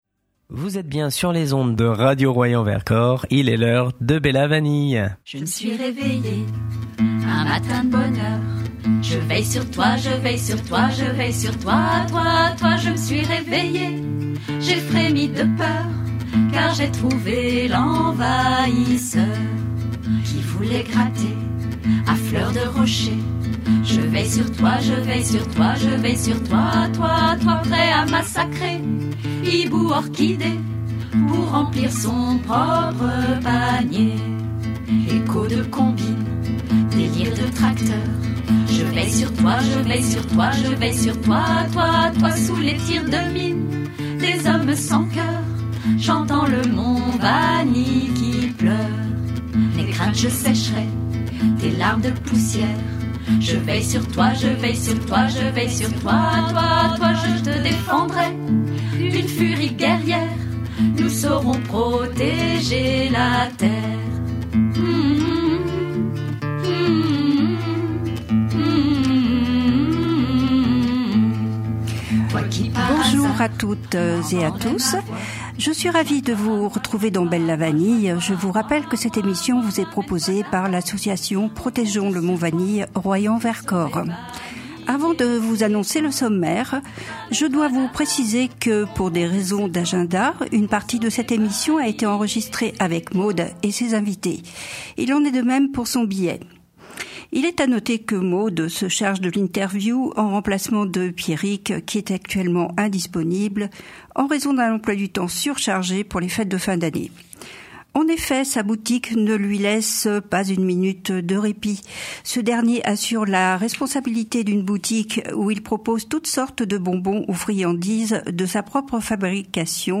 L’équipe de Bella Vanille se retrouve pour une émission et reportages sur les luttes militantes.